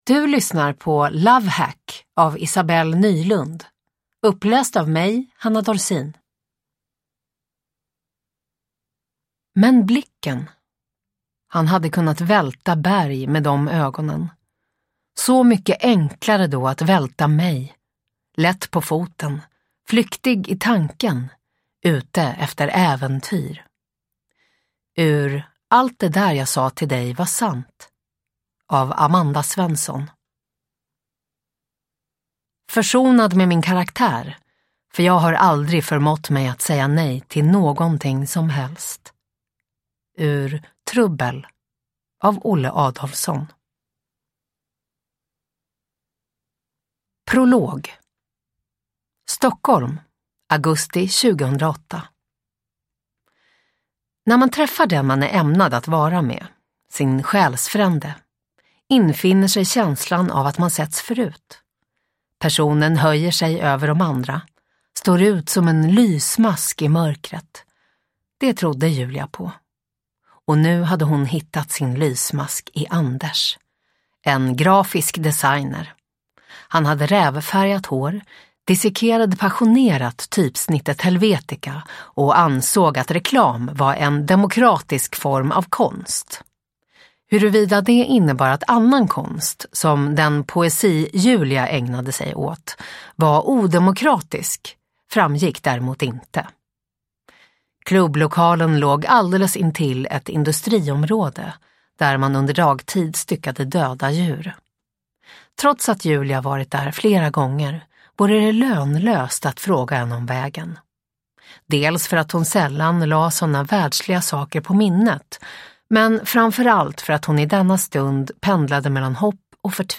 Love hack – Ljudbok – Laddas ner
Uppläsare: Hanna Dorsin